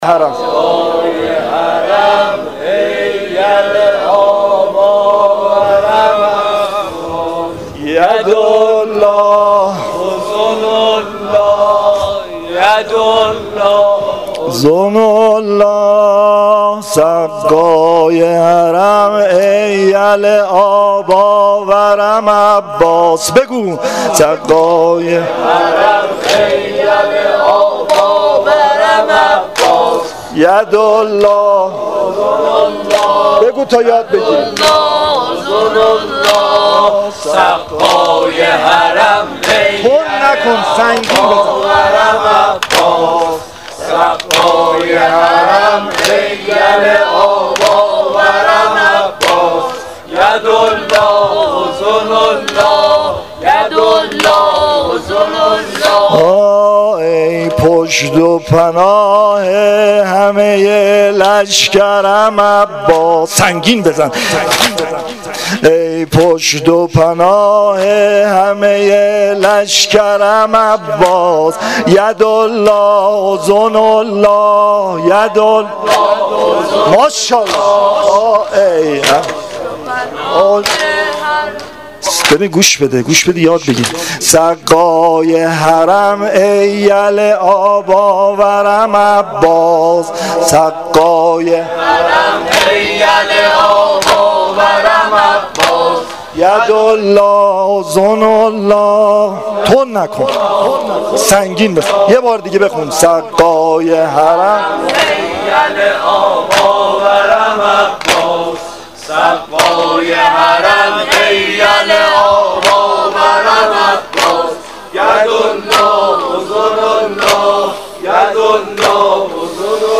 نوحه شب نهم محرم الحرام 1396 (شب تاسوعا)